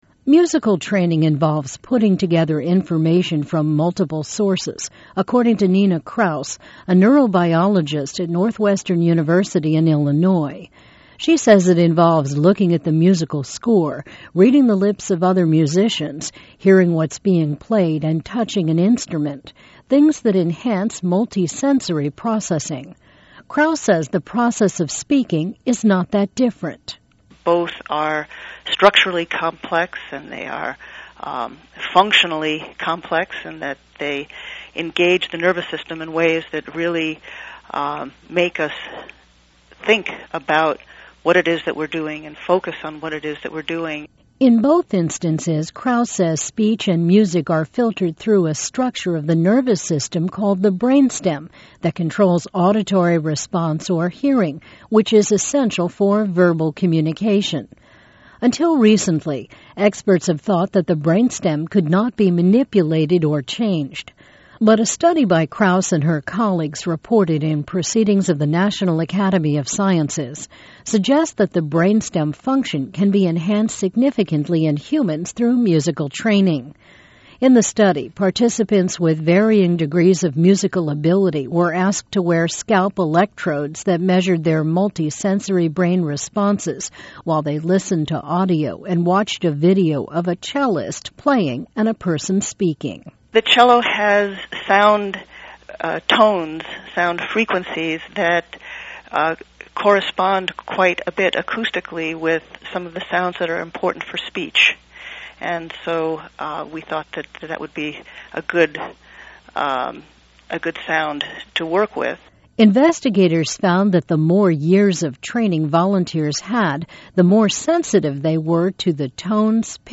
(Source:VOA )